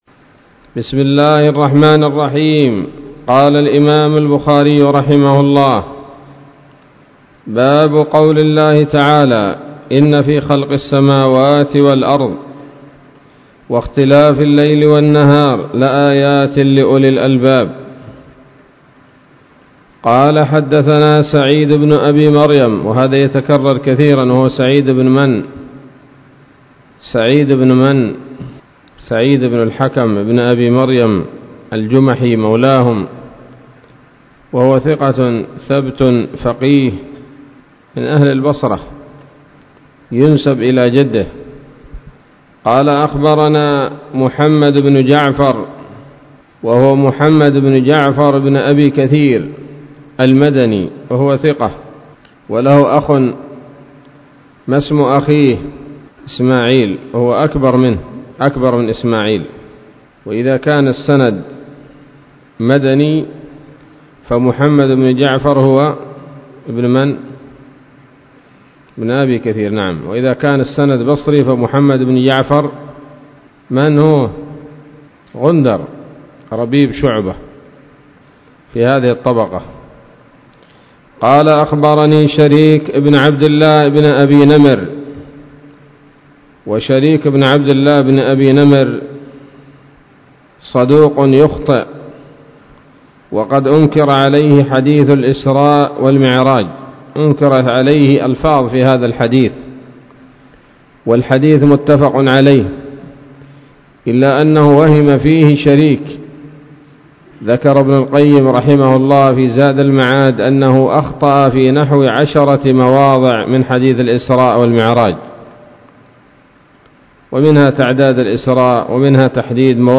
الدرس الستون من كتاب التفسير من صحيح الإمام البخاري